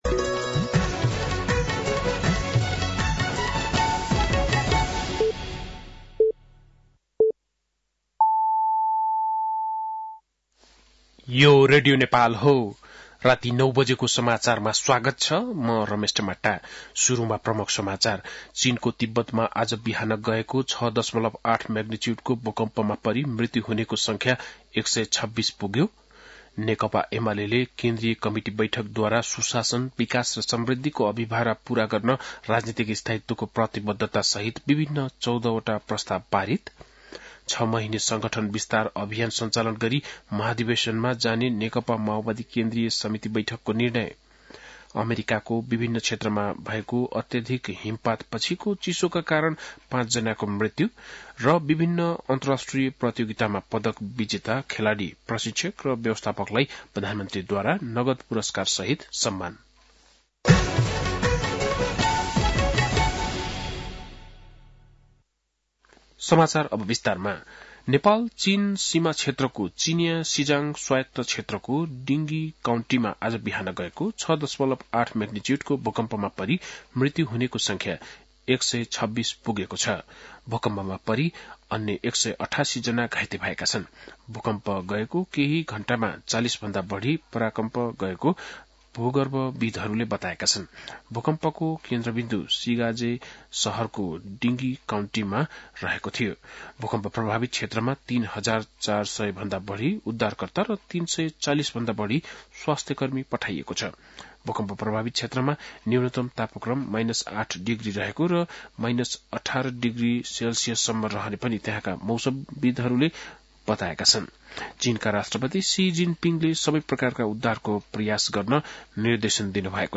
बेलुकी ९ बजेको नेपाली समाचार : २४ पुष , २०८१
9-pm-nepali-news-9-23.mp3